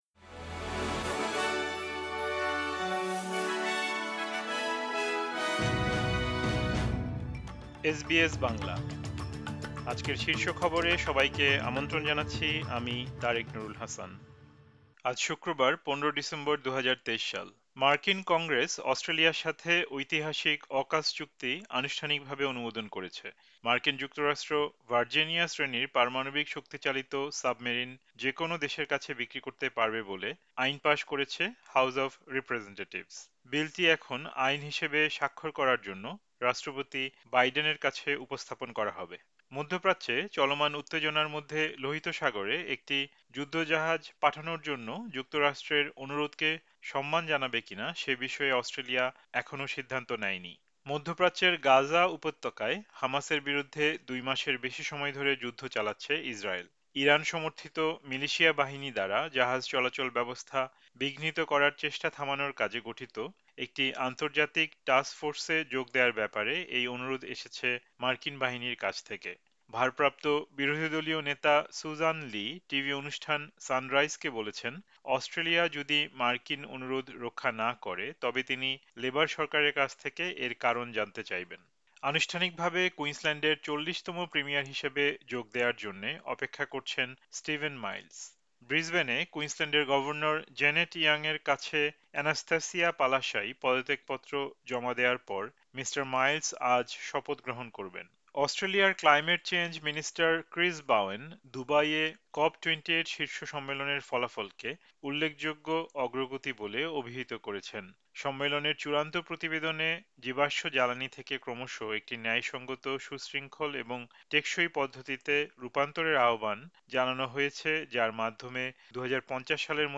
এসবিএস বাংলা শীর্ষ খবর: ১৫ ডিসেম্বর, ২০২৩